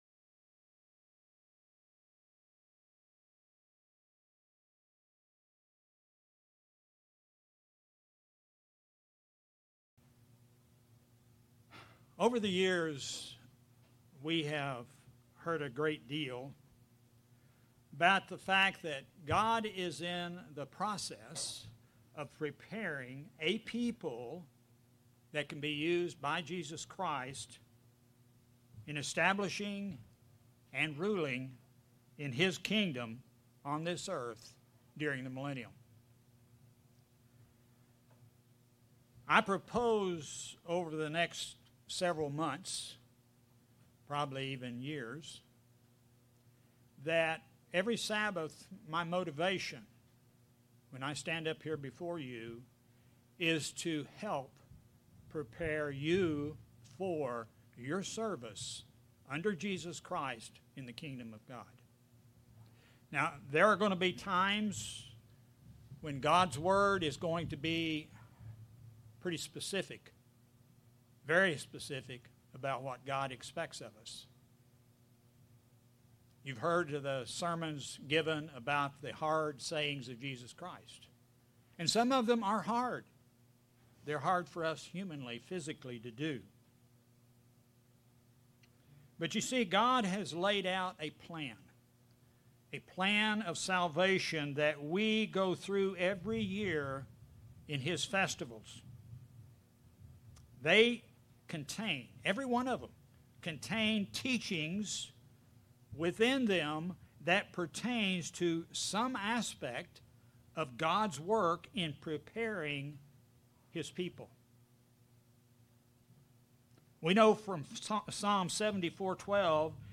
Sermon
Given in Springfield, MO